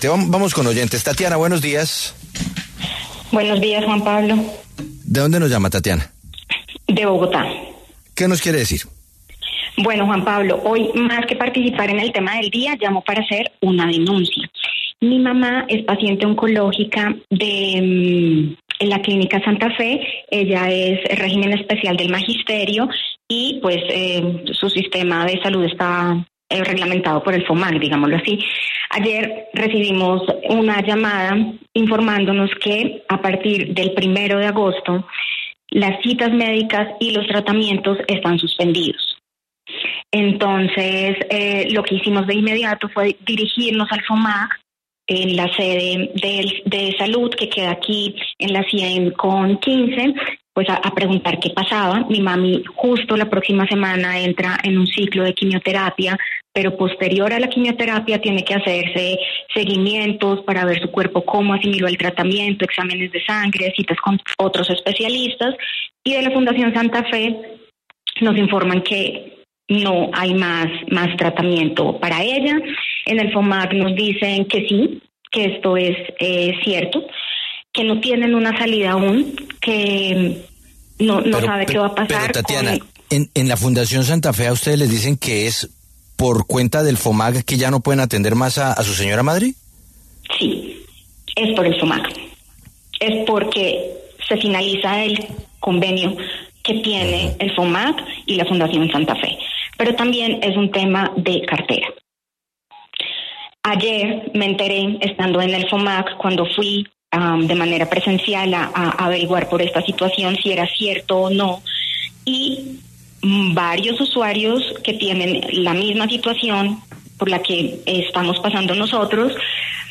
La secretaria de Educación de Bogotá, Isabel Segovia, habló en La W de la situación de la satención en salud para los maestros.